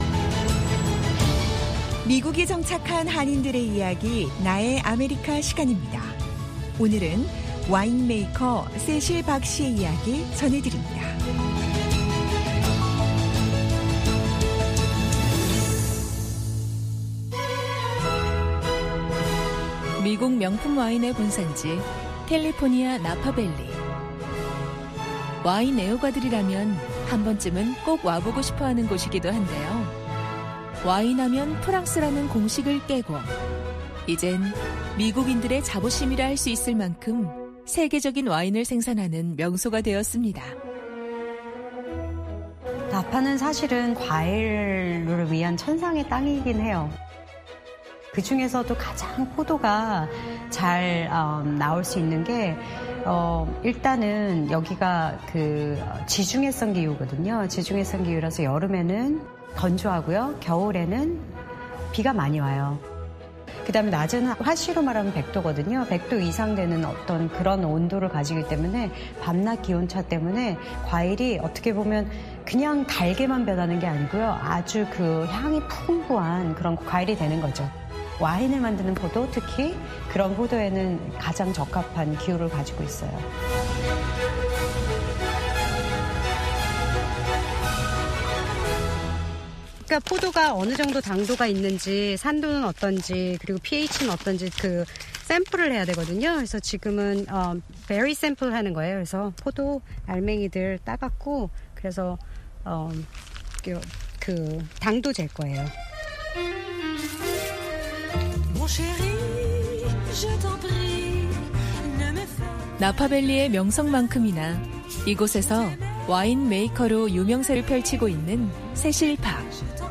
VOA 한국어 방송의 일요일 오전 프로그램 2부입니다. 한반도 시간 오전 5:00 부터 6:00 까지 방송됩니다.